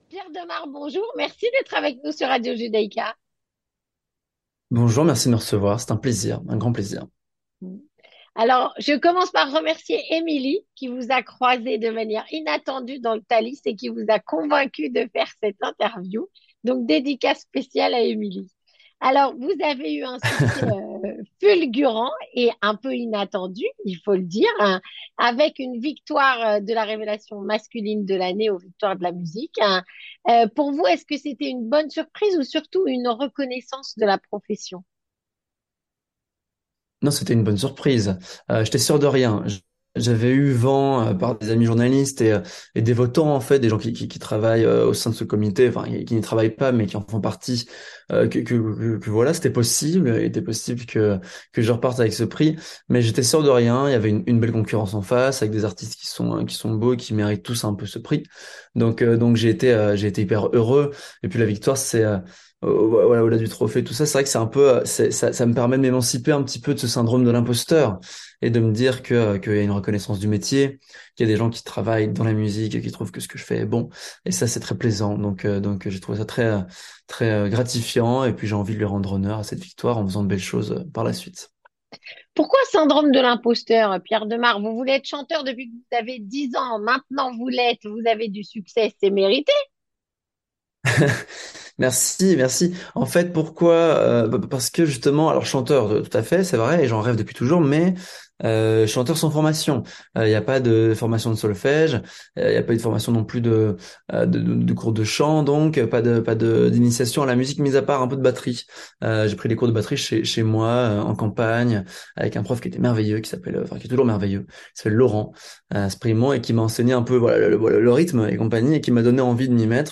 Rencontre avec Pierre de Maere, Chanteur, Révélation masculine de l'année 2023